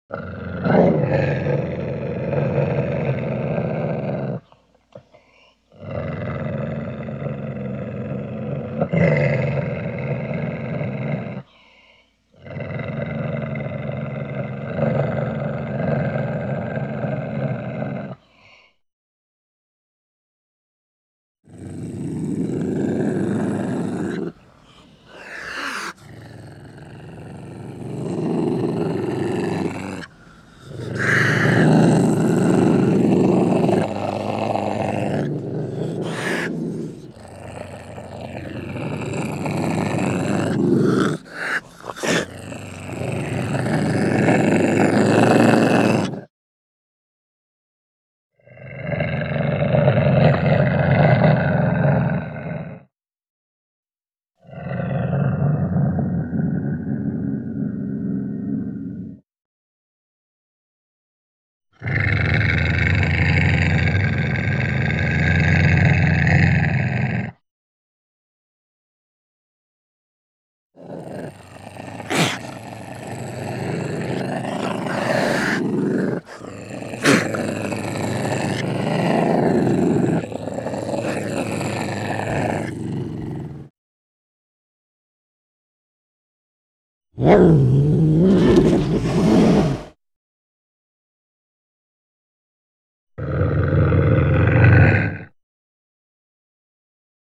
animal
Ocelot Growls with Inhales and Hisses